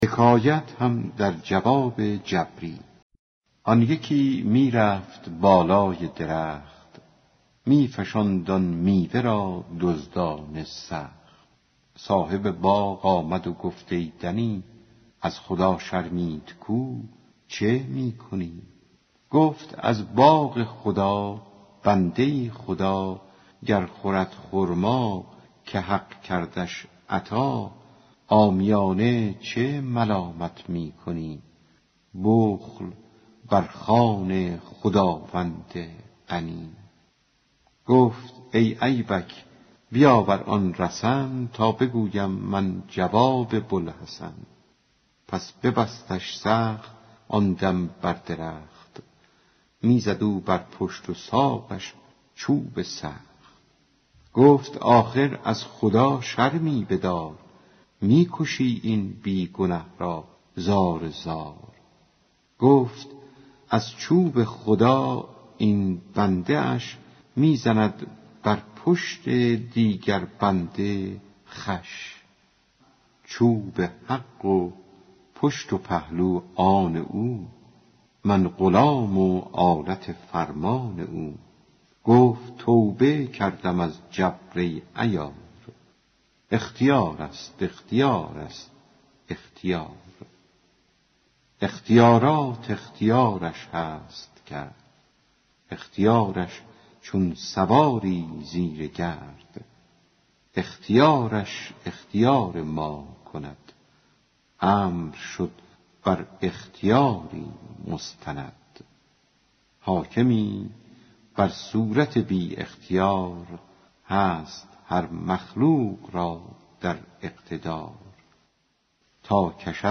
دکلمه رفتن شخصی بالای درخت و خوردن و ریختن میوه ها